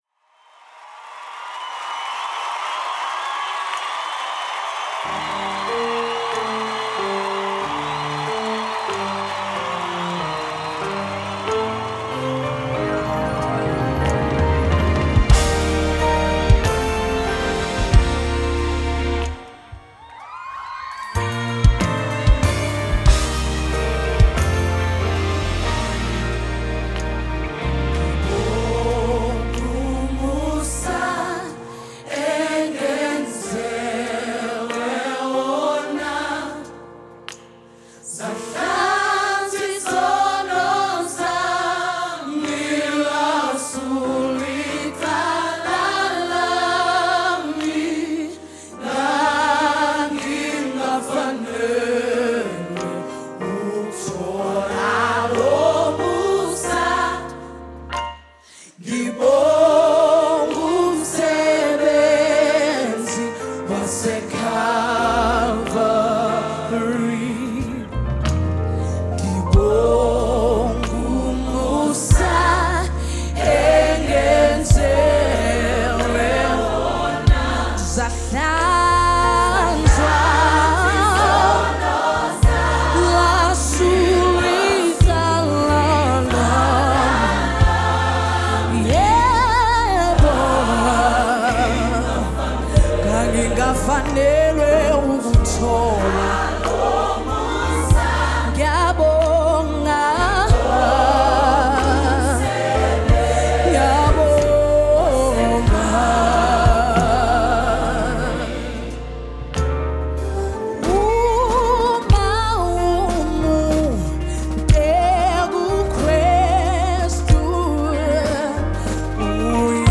South African Choir Band
gospel praise session